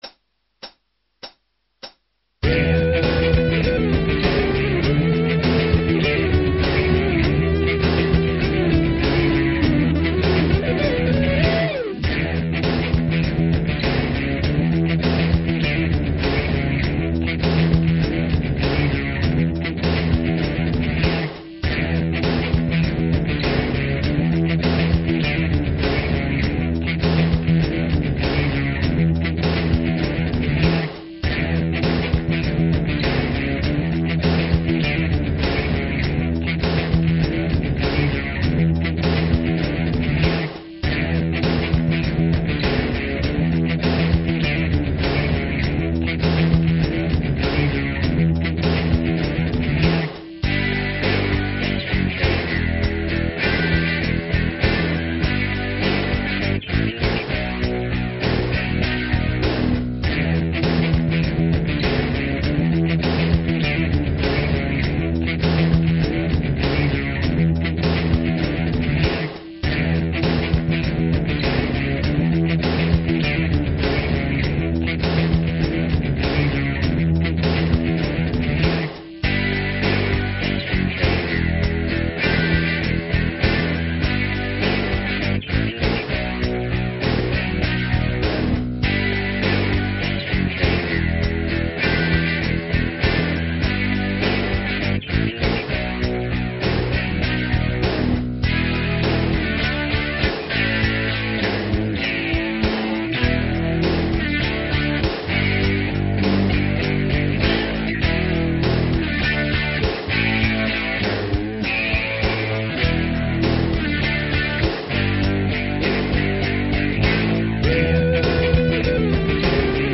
ハードブルース系